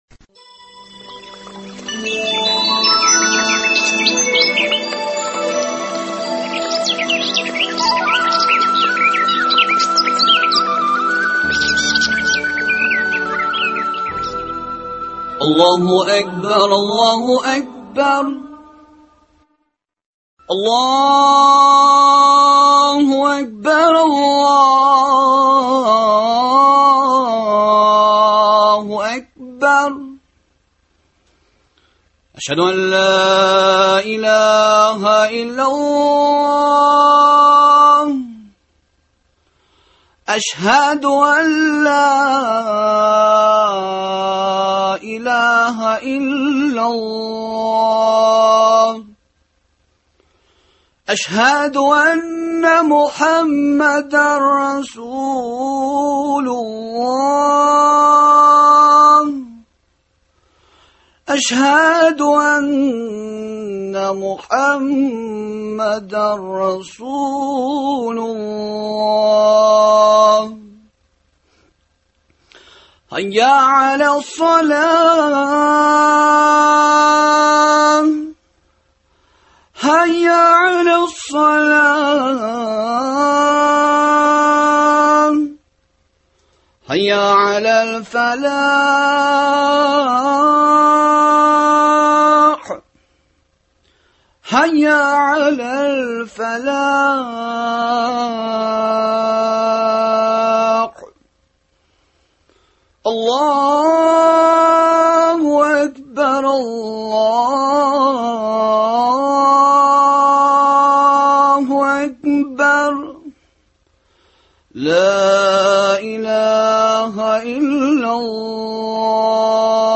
әңгәмәләр циклы